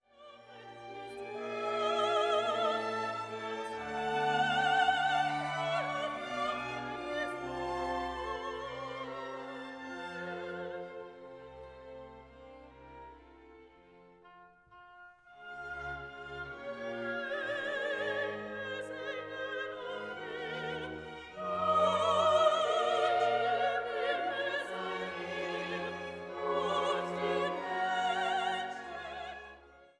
für Sopran, Bariton, Frauenchor und Orchester